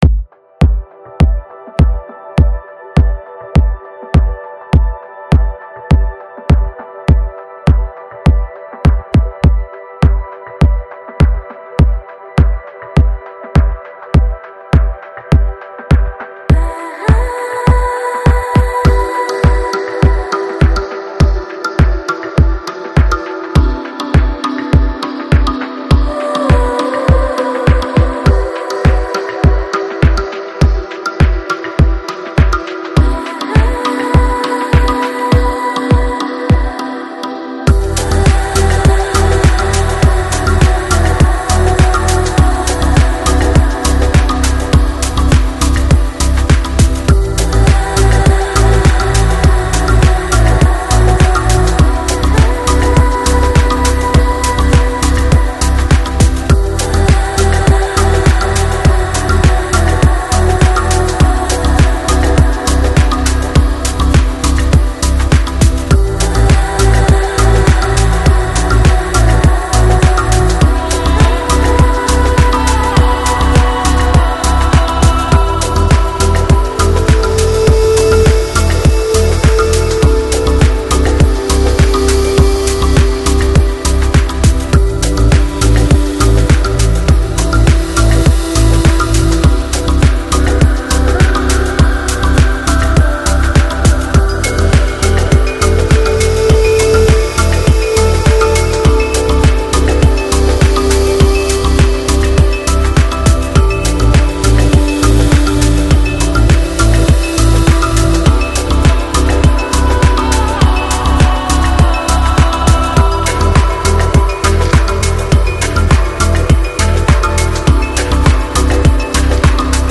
Electronic, Chill House, Lounge, Chill Out Год издания